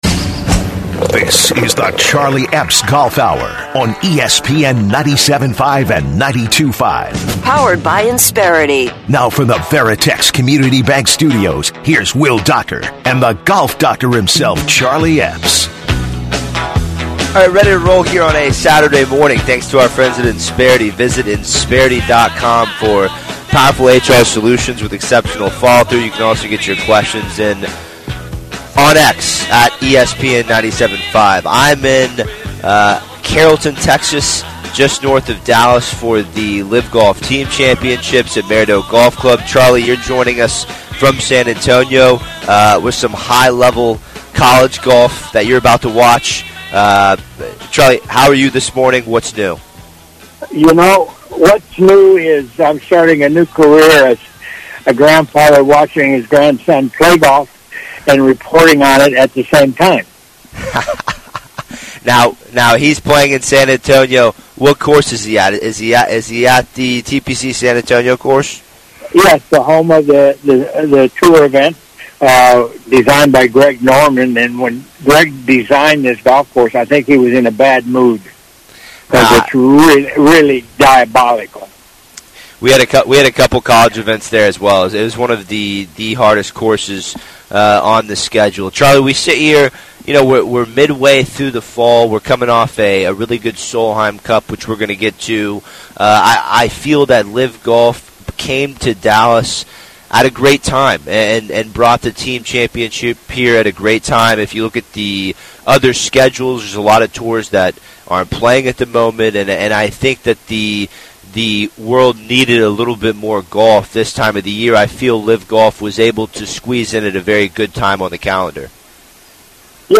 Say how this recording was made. Live from the LIV Golf Team Championship in Dallas